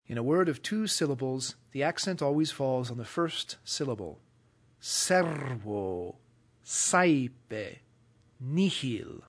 In a word of two syllables the accent always falls on the first syllable: sér-vō , sáe-pe , n�-hil .
word_of_two_syllables.mp3